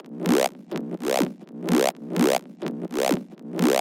更深的电子中低音循环
Tag: 126 bpm Electro Loops Bass Wobble Loops 656.52 KB wav Key : C